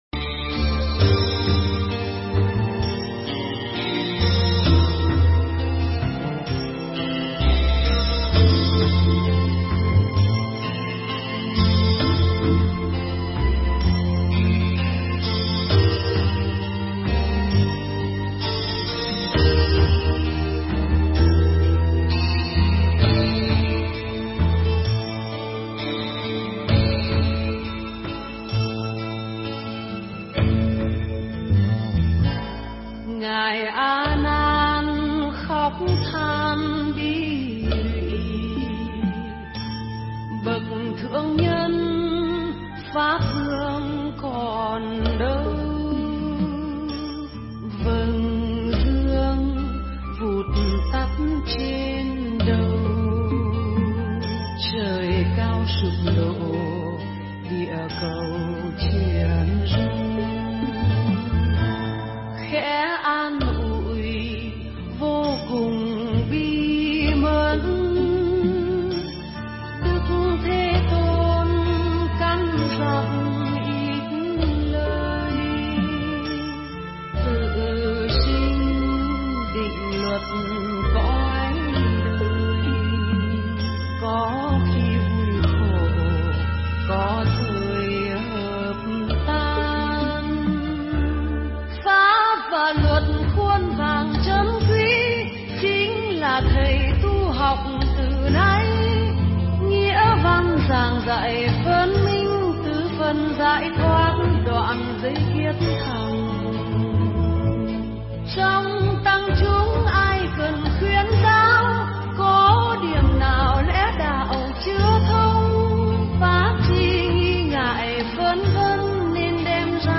Nghe Mp3 thuyết pháp Vấn Đáp Tại Chùa Long Vân